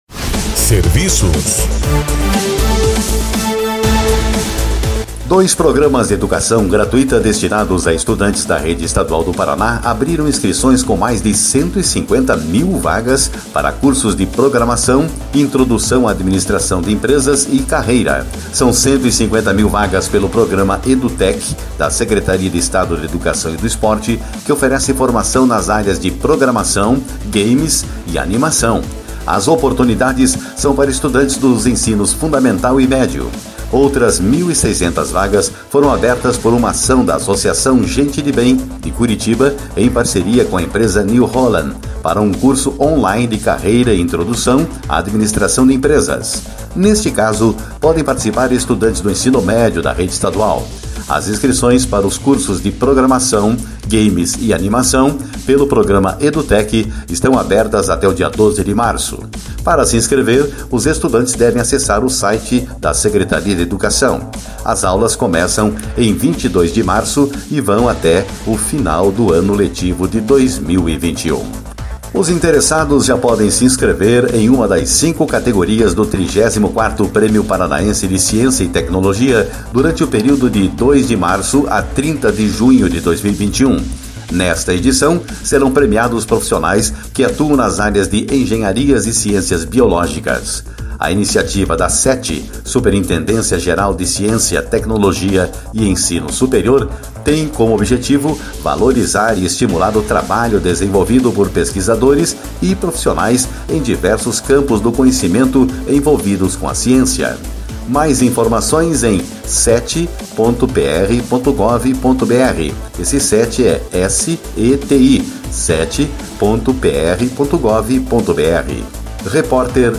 Programas de educação gratuita para estudantes da rede estadual estão com vagas abertas. 34º Prêmio Paranaense de Ciência e Tecnologia valoriza o cientista do Paraná. Mais informações de serviços no boletim